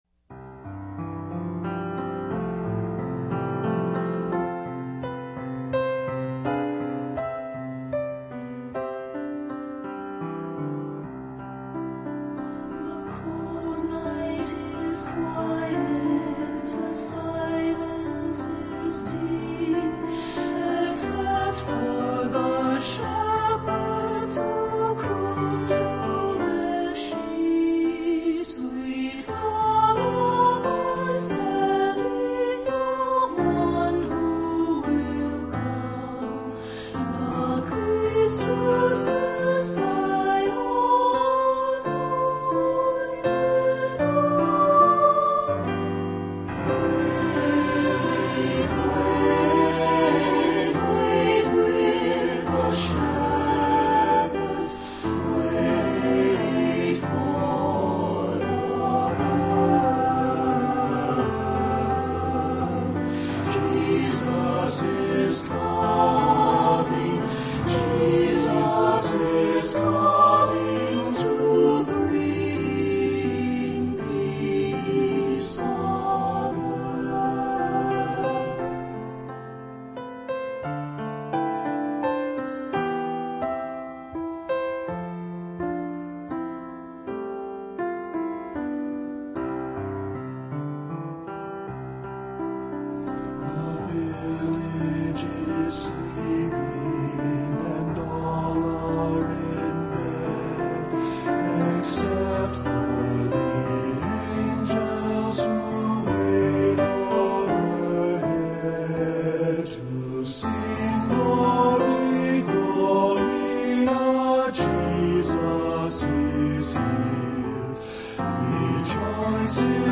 Traditional French Carol
Voicing: Unison|2-Part